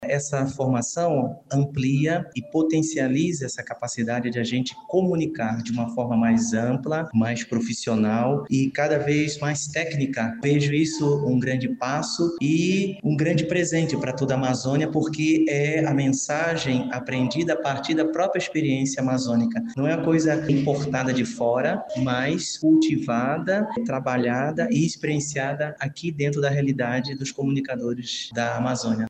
Dom Samuel Ferreira de Lima, bispo auxiliar de Manaus, explica que a formação de novos comunicadores para a Amazônia é essencial, principalmente, para as localidades distantes.